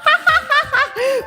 Worms speechbanks
Laugh.wav